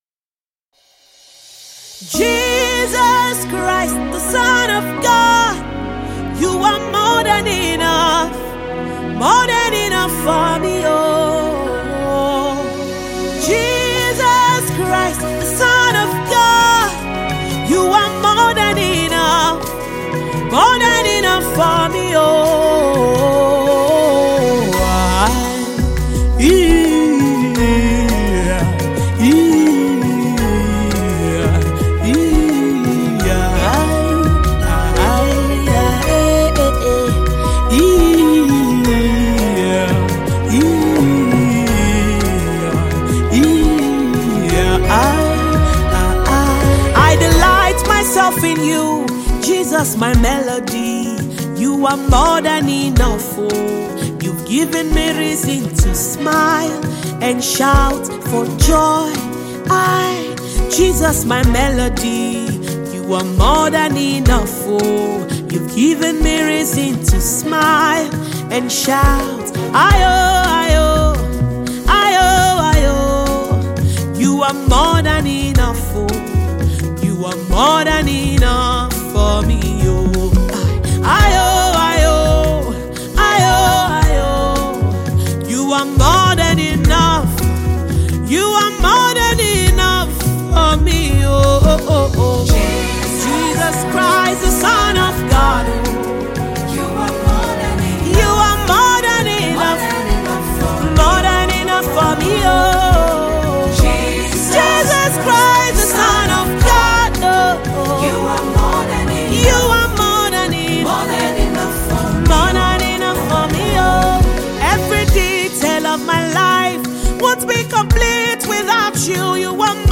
Esteemed Nigerian gospel singer and songwriter